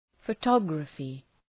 Προφορά
{fə’tɒgrəfı}